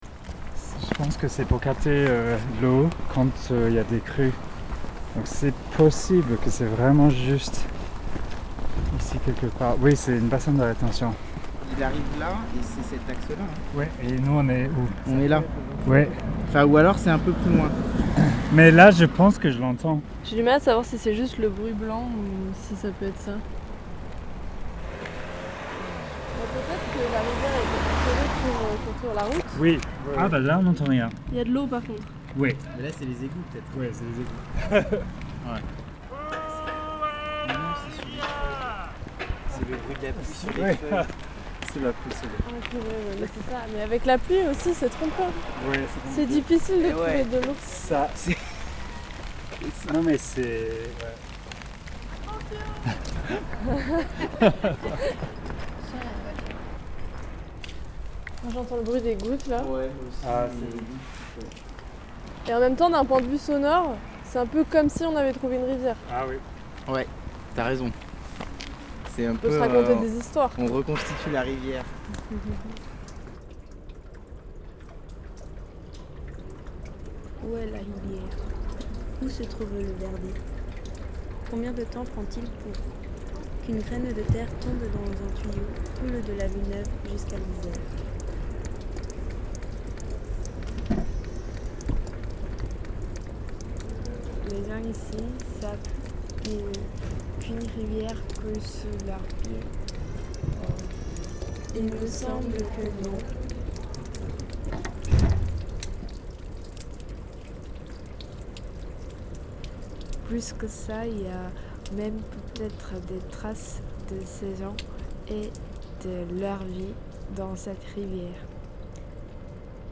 D’une quête collective bien conviviale jusqu’à l’immersion dans ses eaux tourbillonnantes.